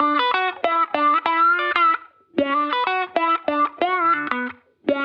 Index of /musicradar/sampled-funk-soul-samples/95bpm/Guitar
SSF_StratGuitarProc1_95B.wav